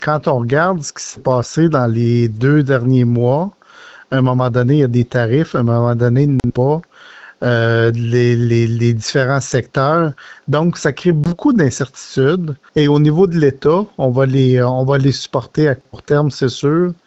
En entrevue, le député de Nicolet-Bécancour est revenu sur les derniers mois du président américain.